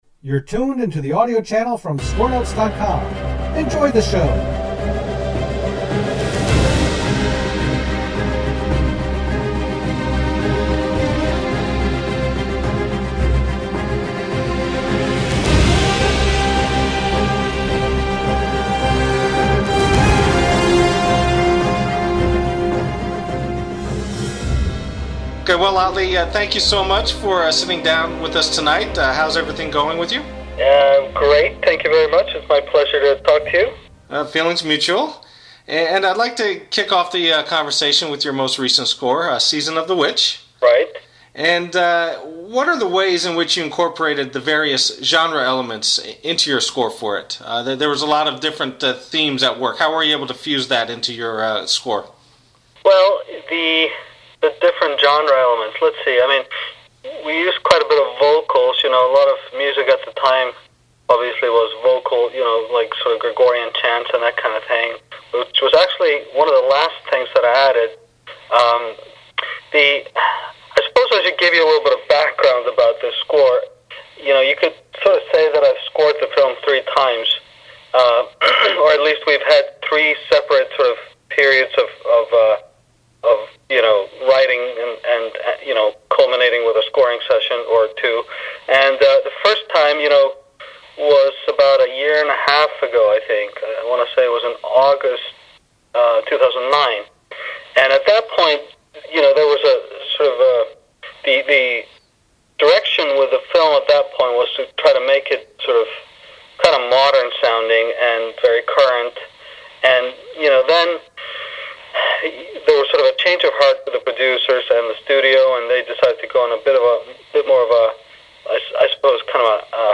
Atli Orvarrson Interview